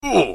hurt.wav